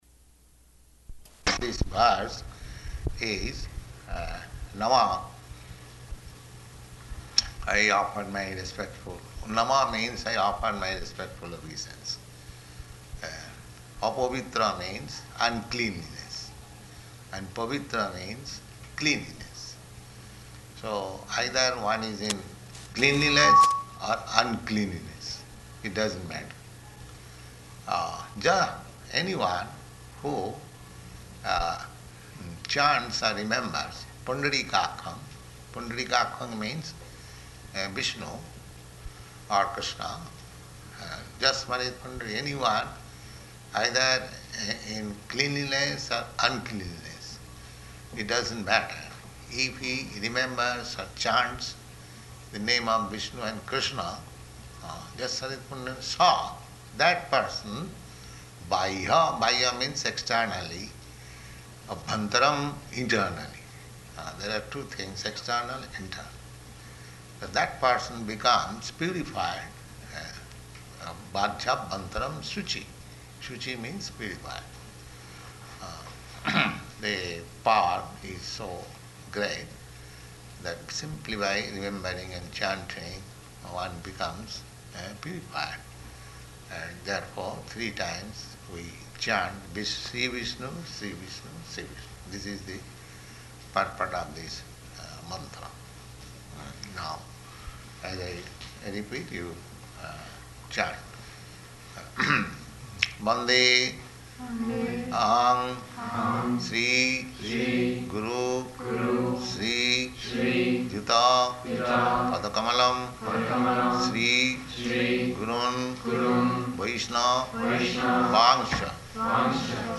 Initiation Lecture
[responsive word-by-word chanting by devotees]